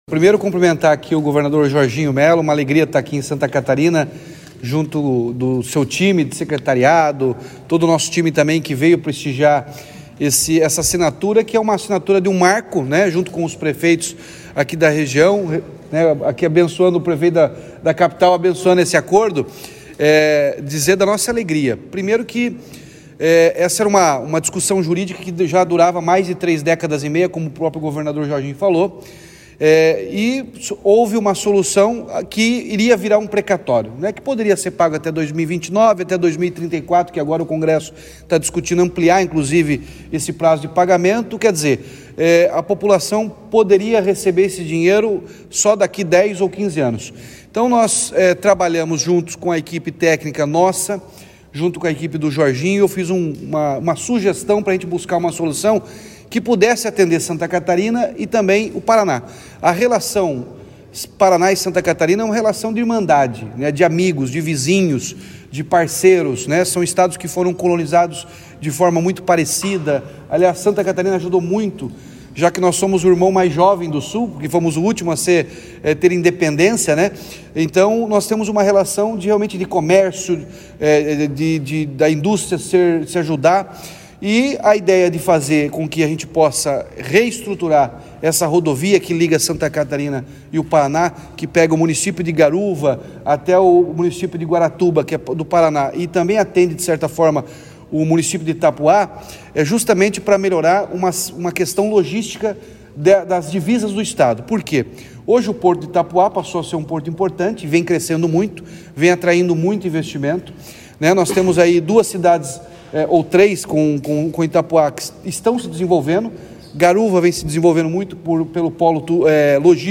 Sonora do governador Ratinho Junior sobre acordo judicial entre Paraná e Santa Catarina